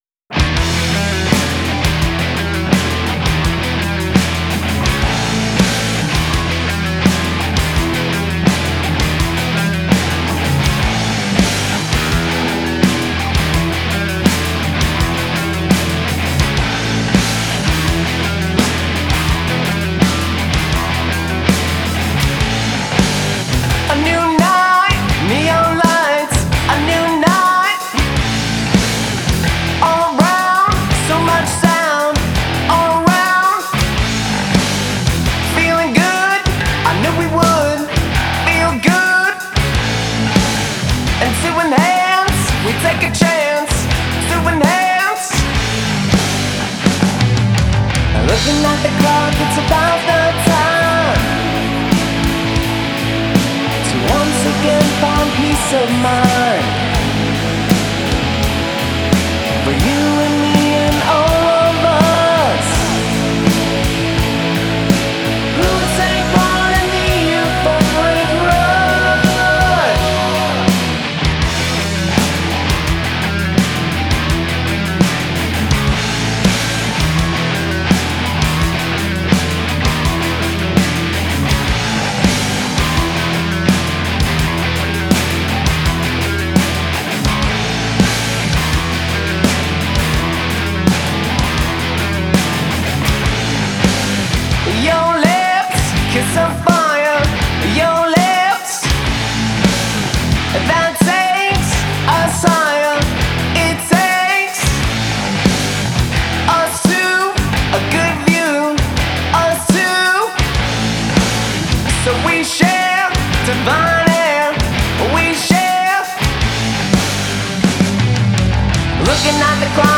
prog rock